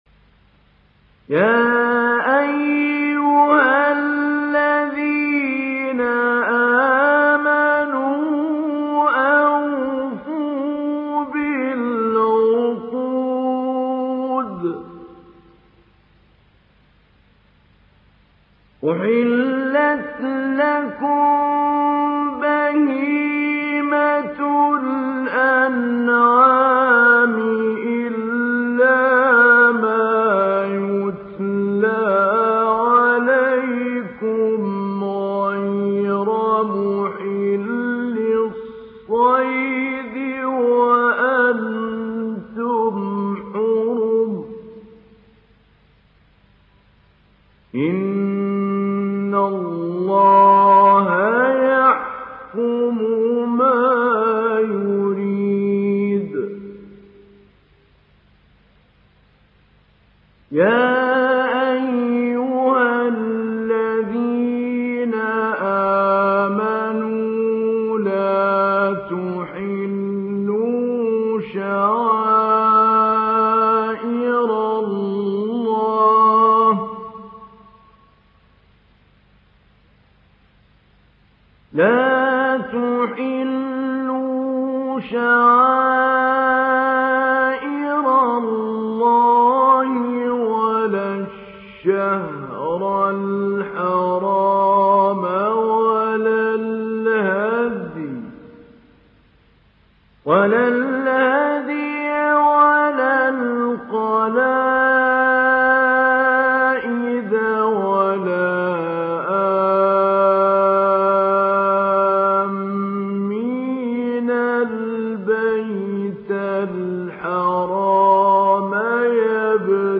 دانلود سوره المائده محمود علي البنا مجود